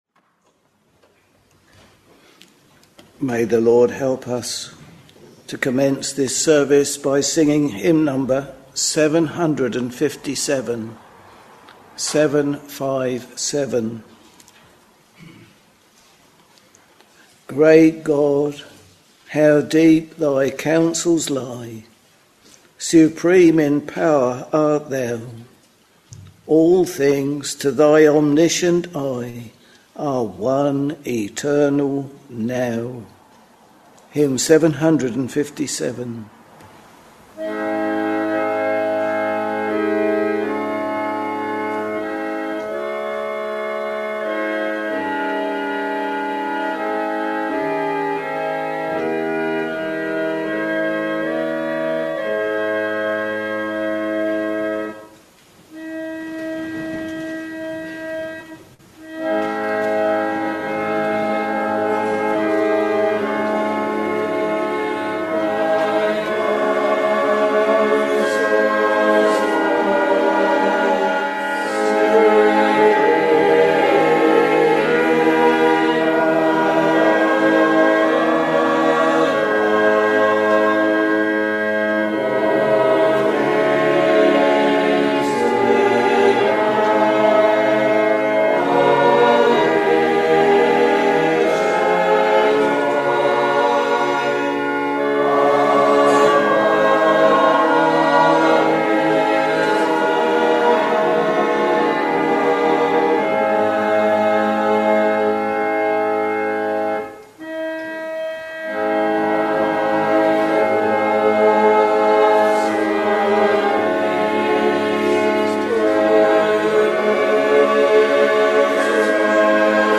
Sunday, 18th August 2024 — Evening Service
Hymns: 757, 119, 387 Reading: 1 Corinthians 1,2,3